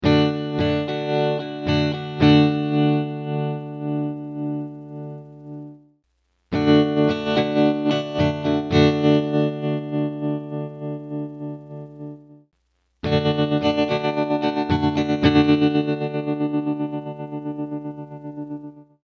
A classic tremolo.
Clip 2: 50% Depth
all clips: Rate at 0%, then 50%, then 100%
guitar - effect - cabinet simulator - sound card